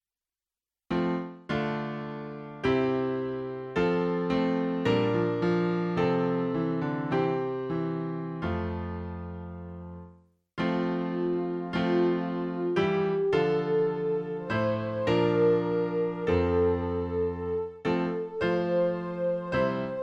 Klavier-Playback zur Begleitung der Gemeinde MP3 Download